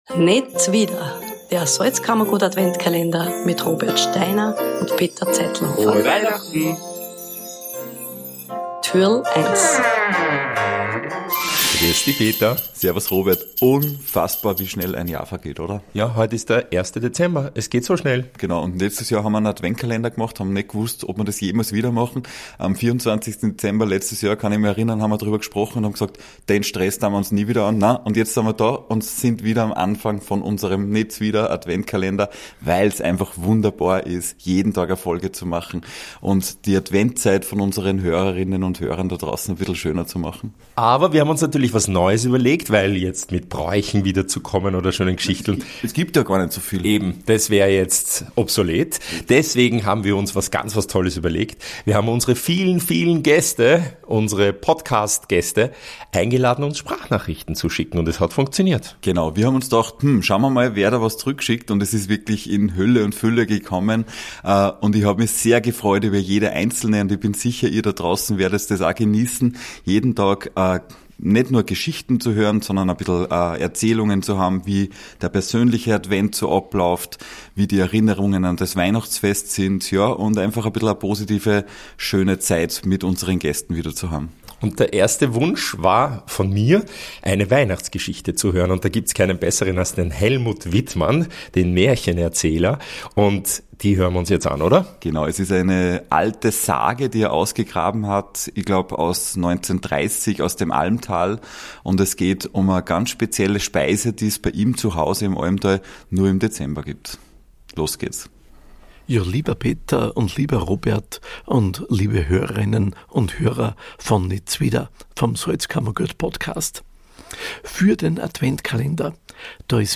Eine Weihnachtssage